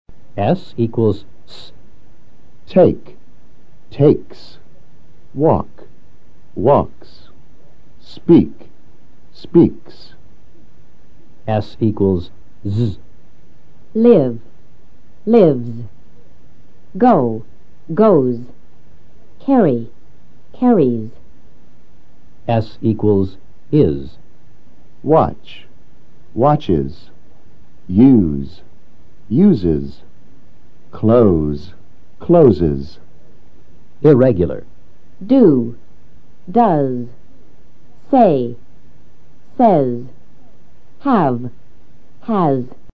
En el tercer sonido la z suena sibilante.
Escucha atentamente e intenta repetirlos después del profesor.
s = / S /